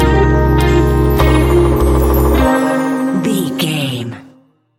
Ionian/Major
C♭
chilled
laid back
Lounge
sparse
new age
chilled electronica
ambient
atmospheric